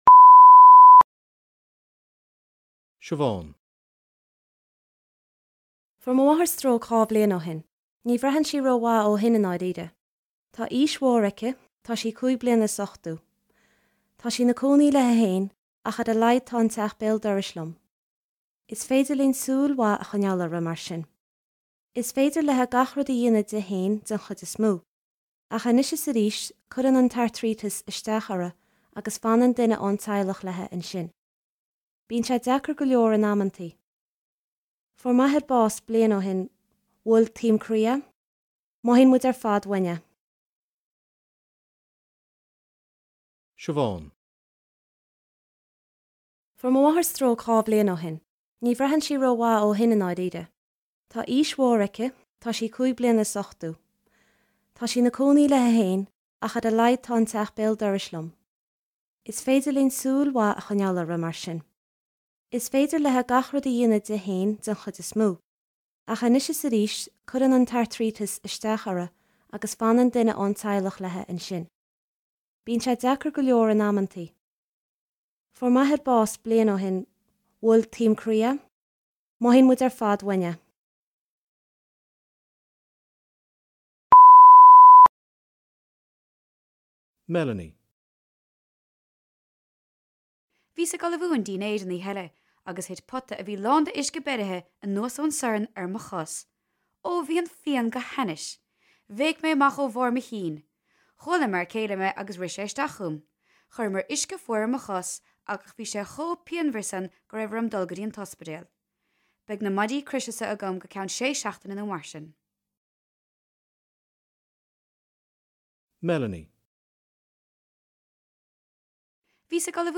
B1.11 Cluastuiscint
Cleachtaí cluastuisceana ina mbeidh tú ag éisteacht le daoine ag caint faoi chúrsaí sláinte agus tréithe daoine.